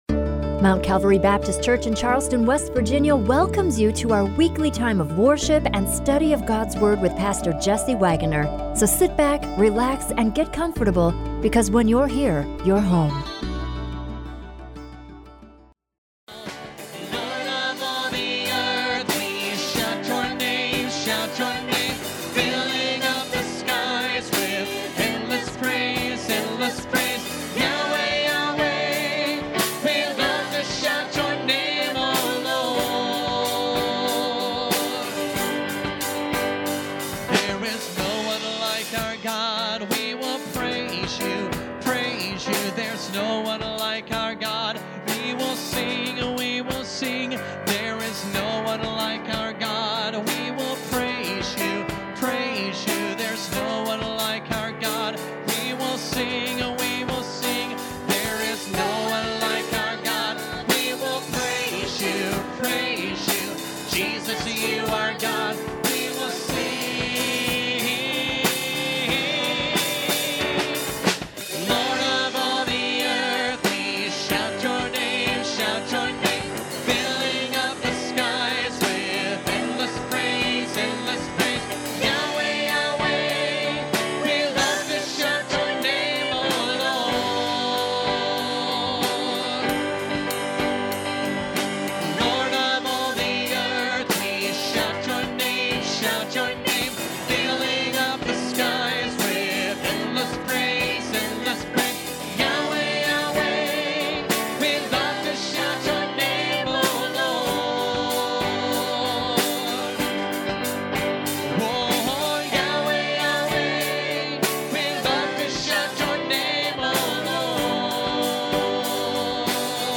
The Praise team sings “Keep Me in the Moment”.